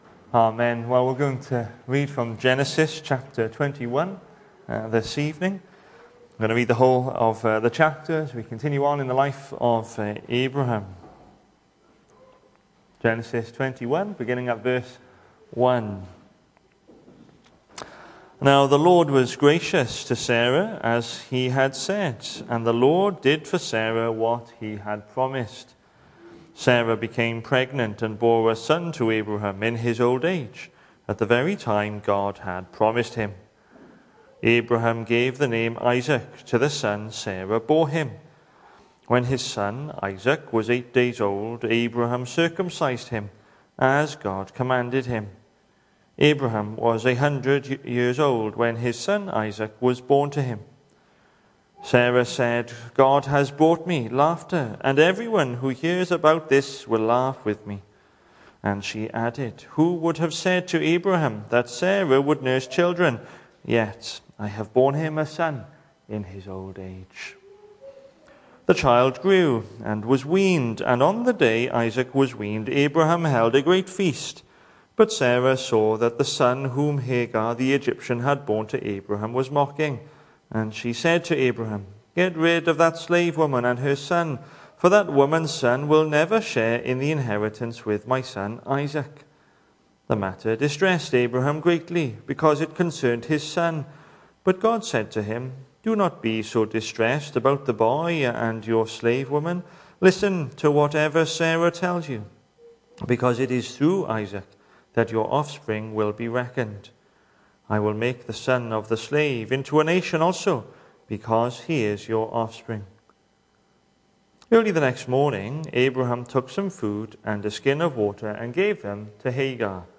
The 8th of February saw us hold our evening service from the building, with a livestream available via Facebook.
Sermon